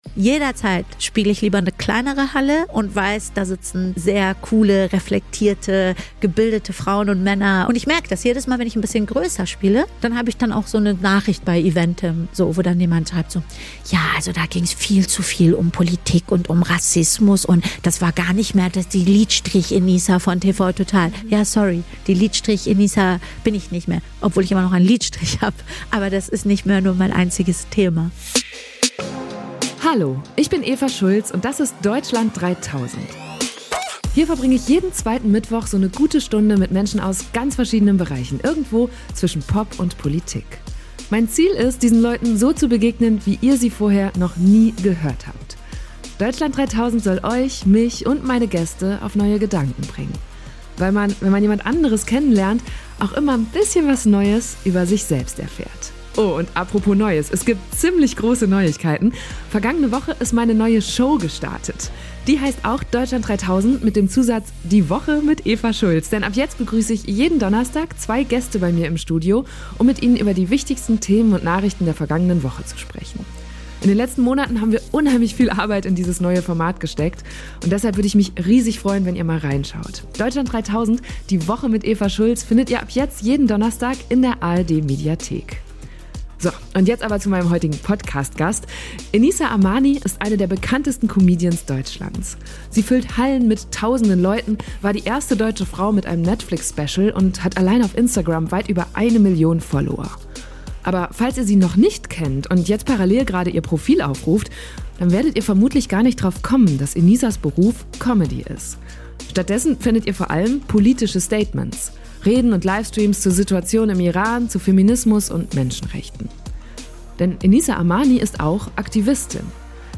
Wir haben diese Folge in Enissas Büros in Offenbach am Main aufgenommen. Da saßen wir zusammen auf dem Sofa und haben ihren Lieblingstee getrunken.